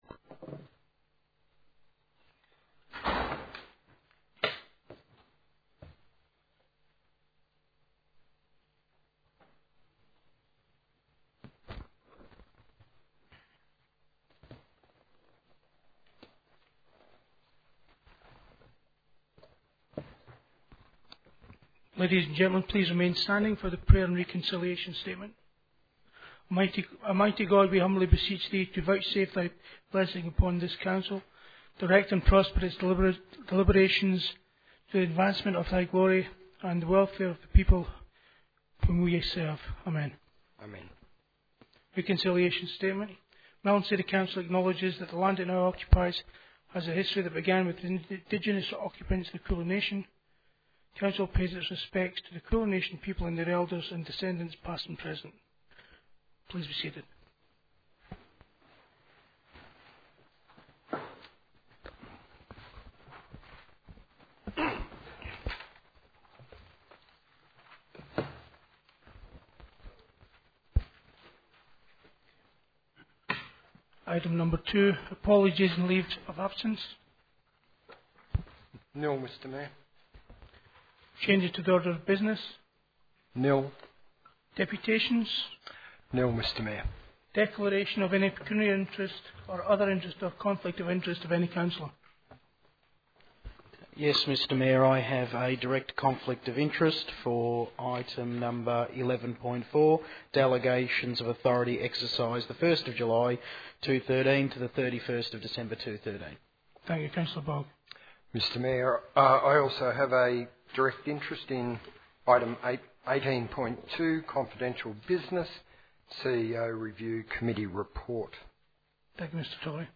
4 February 2014 - Ordinary Council Meeting
4_feb_2014_meeting.mp3